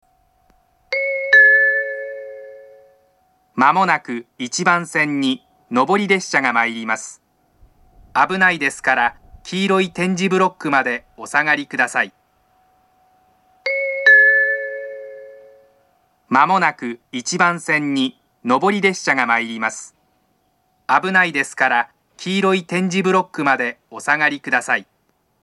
首都圏でも聞くことのできる仙石型の放送で、２回流れます。
接近放送前のチャイムが上下で異なるのはそのままです。
１番線接近放送